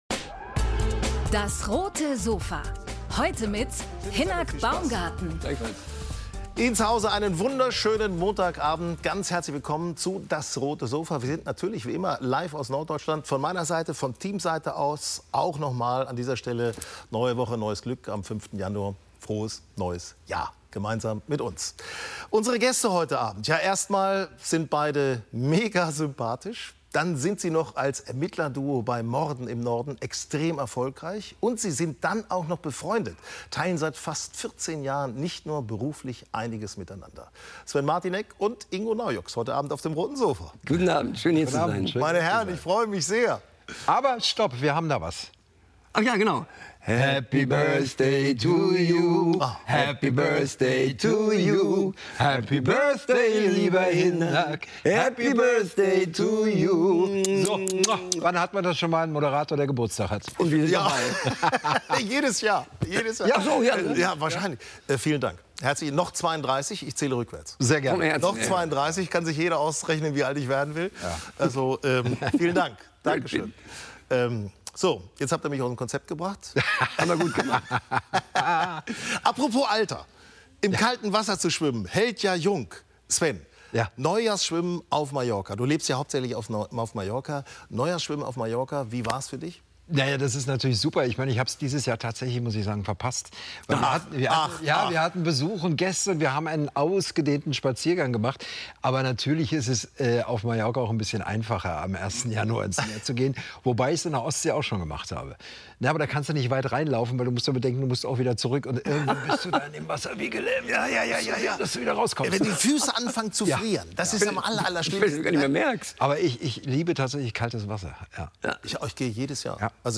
Schauspieler Sven Martinek und Ingo Naujoks über gute Vorsätze im neuen Jahr ~ DAS! - täglich ein Interview Podcast